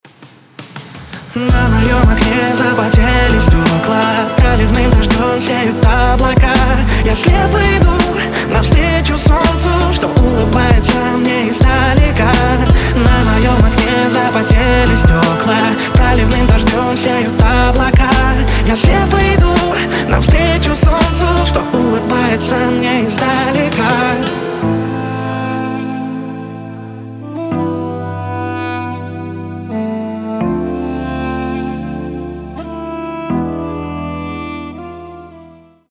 поп , романтические
дудук